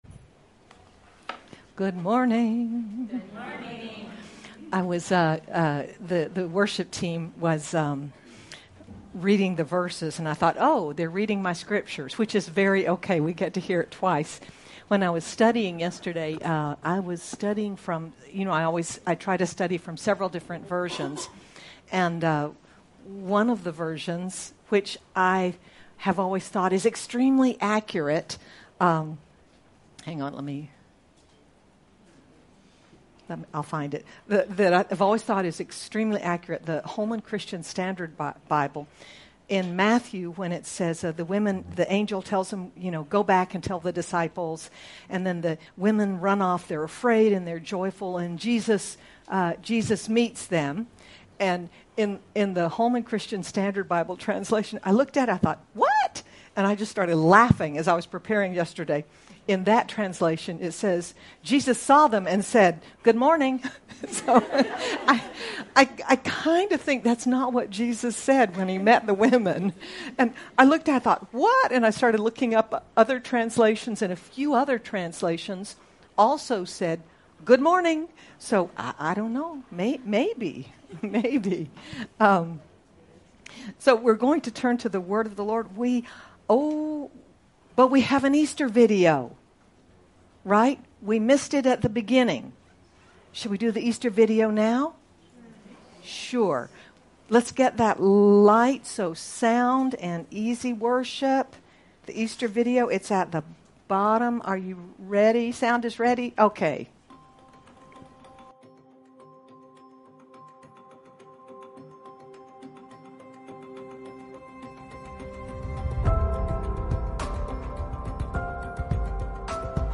Mar 31, 2024 The Reason the Son of God Appeared MP3 SUBSCRIBE on iTunes(Podcast) Notes Discussion In this Easter message, we look at why Jesus came—to destroy the works of the devil—and what that reality means to our lives. Sermon By